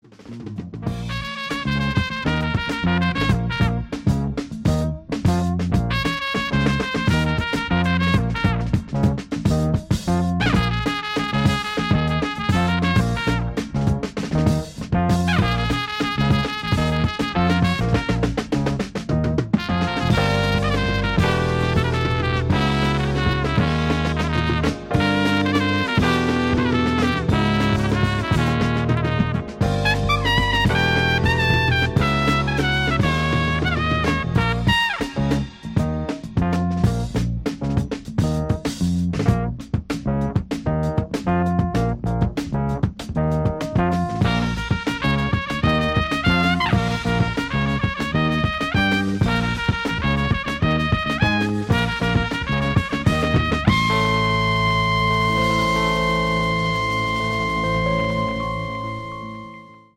Trumpeter